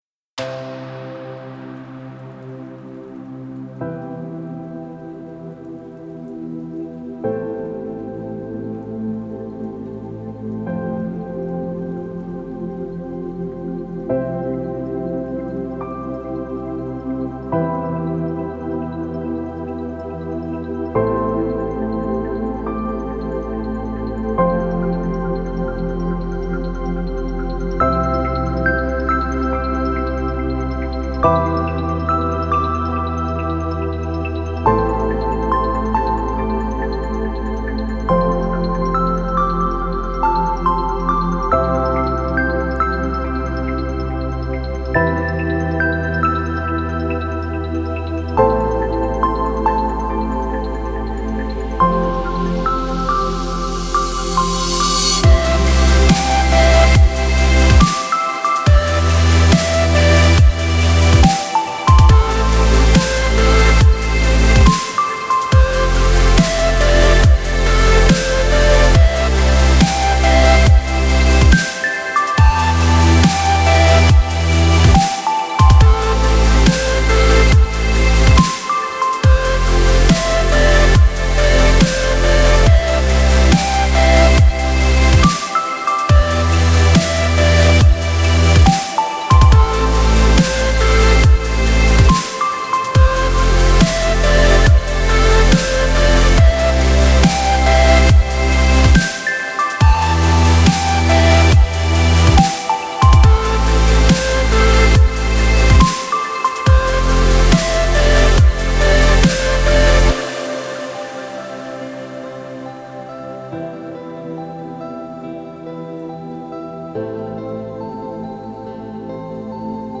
это мощная композиция в жанре альтернативного рока